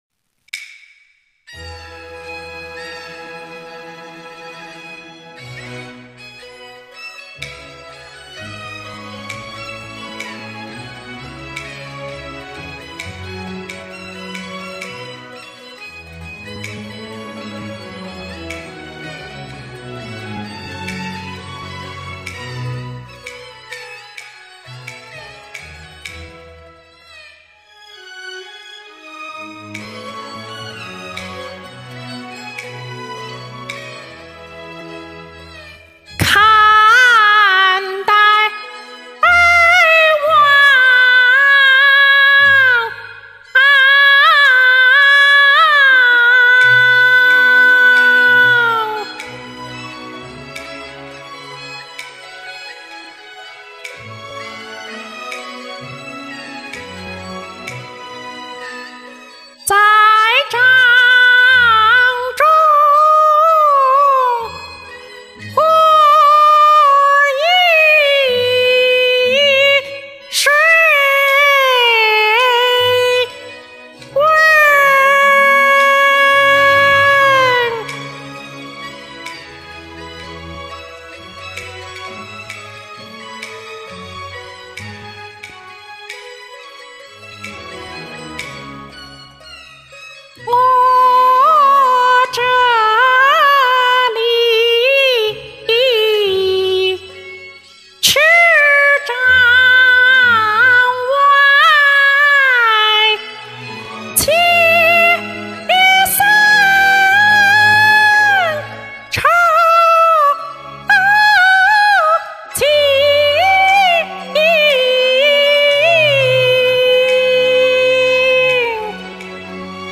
再上傳一段看大王，也是今晚剛錄的。伴奏是全民史依弘交響樂伴奏。